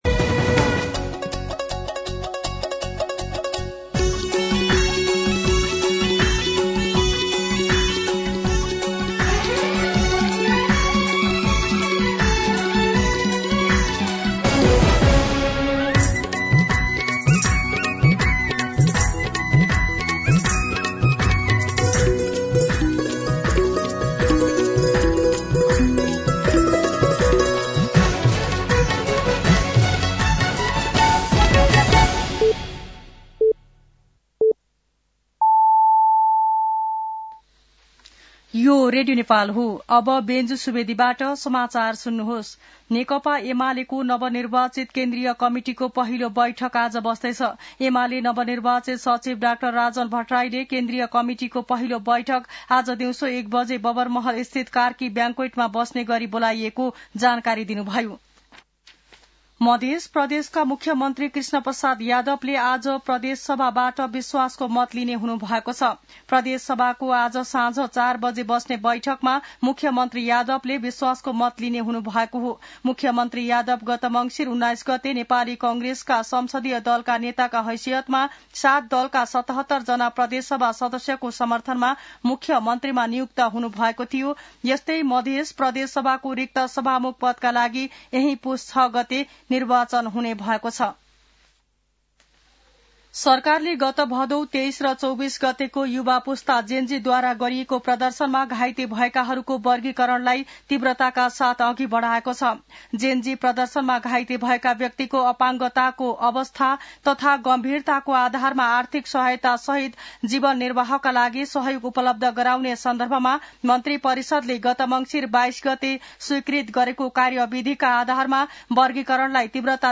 मध्यान्ह १२ बजेको नेपाली समाचार : ४ पुष , २०८२